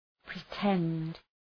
Προφορά
{prı’tend}